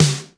HR16B SNR 09.wav